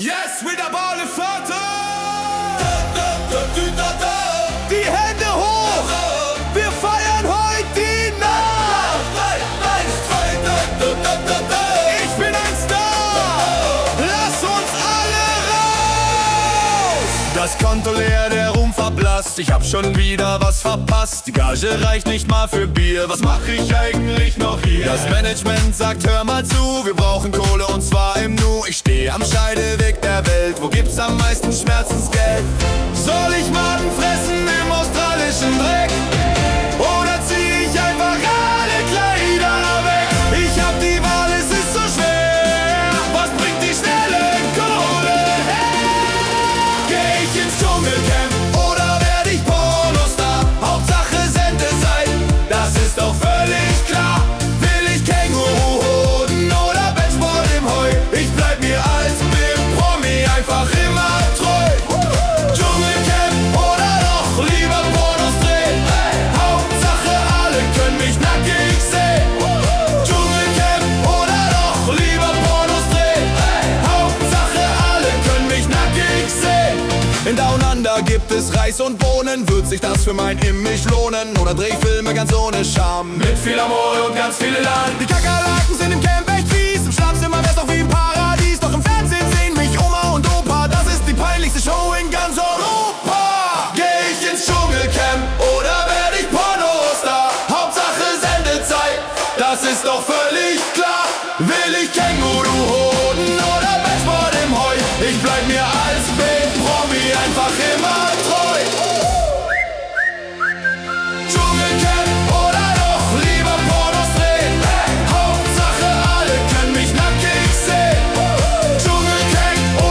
Mallorca Dance Version